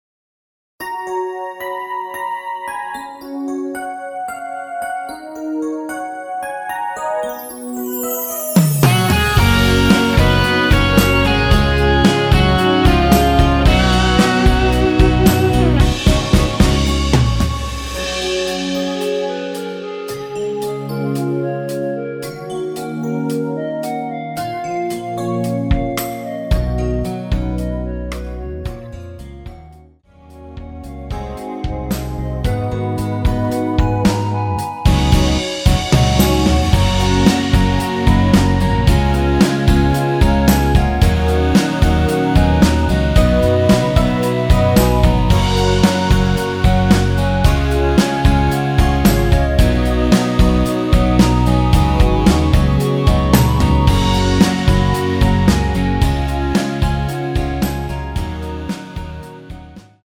원키에서(-1)내린 멜로디 포함된 MR입니다.
F#
앞부분30초, 뒷부분30초씩 편집해서 올려 드리고 있습니다.